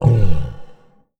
MONSTER_Ugh_02_mono.wav